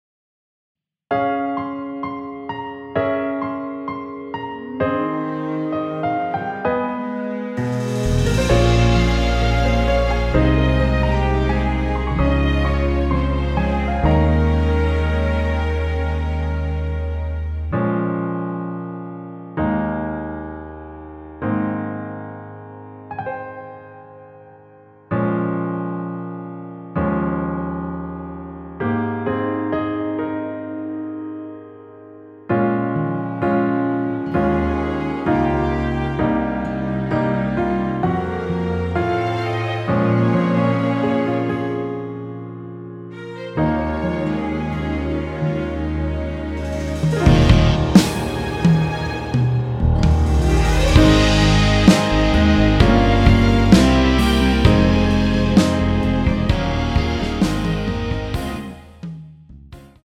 원키에서(-2)내린 MR입니다.
Ab
앞부분30초, 뒷부분30초씩 편집해서 올려 드리고 있습니다.